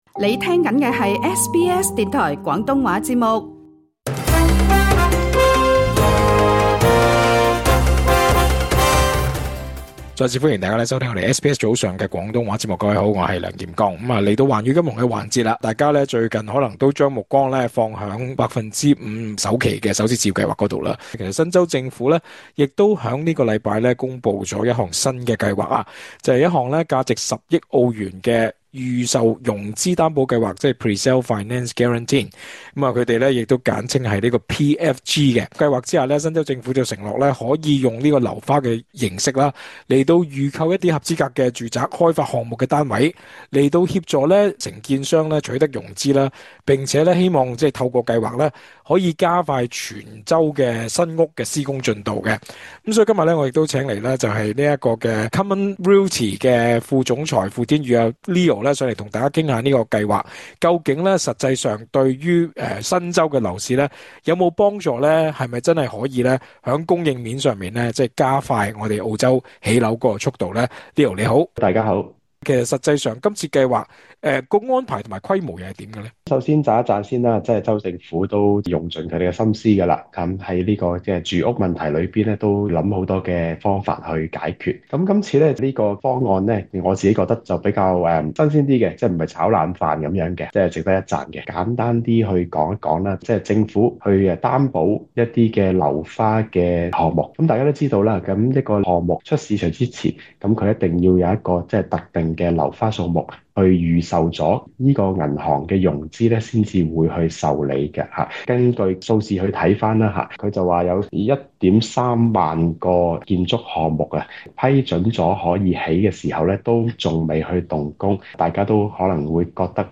詳盡訪問： LISTEN TO 新州政府推新招保樓市供應成效有幾大？